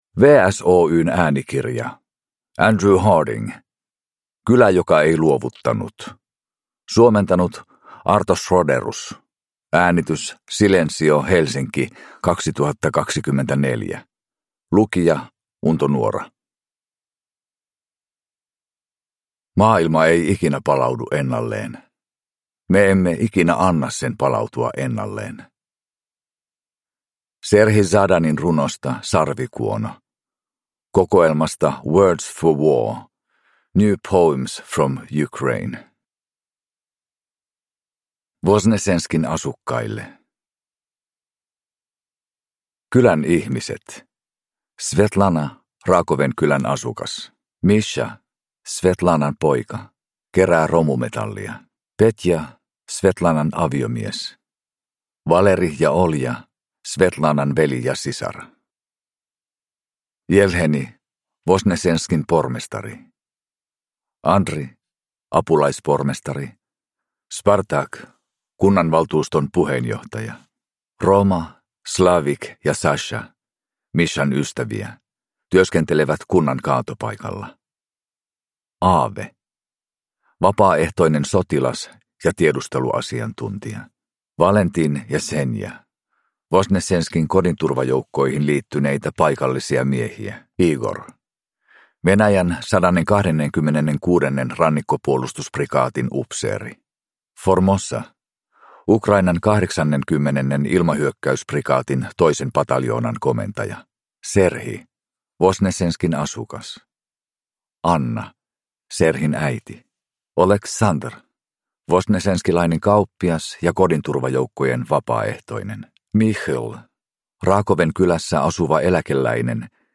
Kylä joka ei luovuttanut – Ljudbok